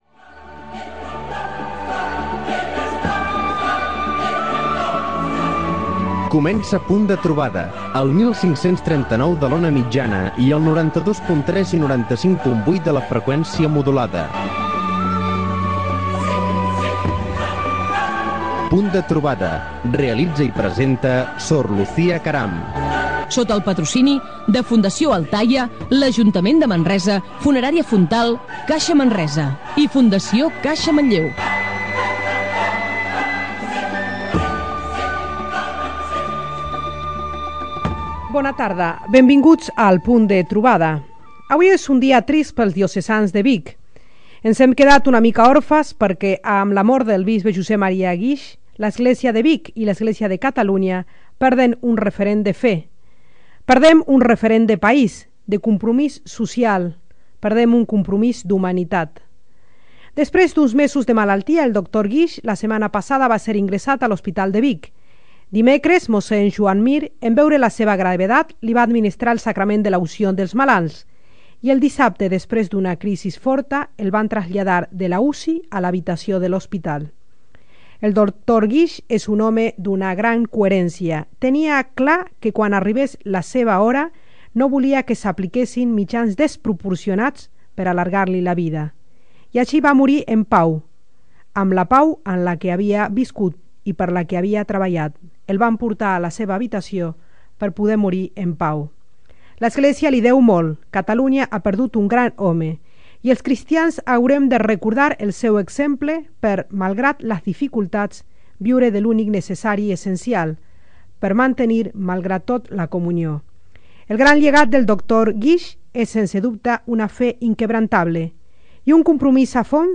5be09ae604b01e5b1f5004bbfb19ed026c5267d8.mp3 Títol SER Catalunya Central Emissora Ràdio Manresa Cadena SER Titularitat Privada local Nom programa Punt de trobada (Ràdio Manresa) Descripció Careta del programa, amb les freqüències d'emissió en OM i FM i els noms dels patrocinadors. Comentari sobre la mort del bisbe de Vic Josep Maria Guix. Entrevista